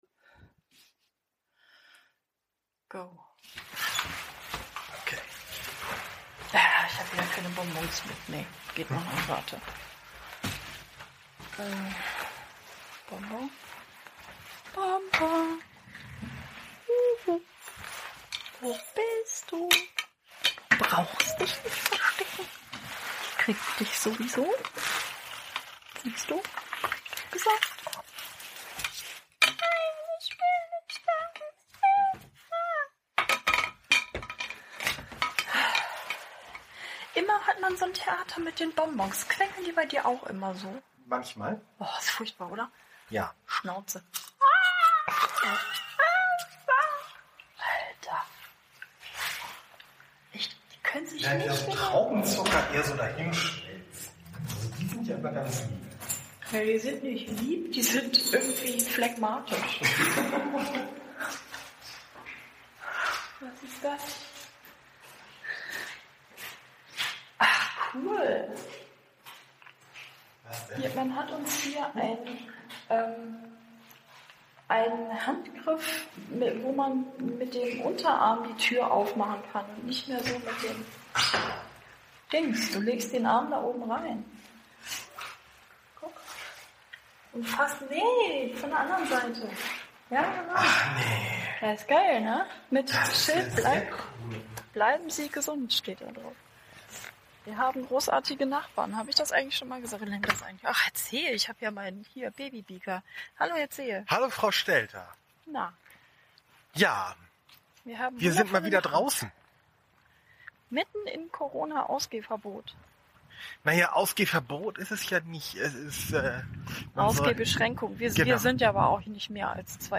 Solange wir noch raus dürfen, spazieren wir sabbelnd durch Barmbek.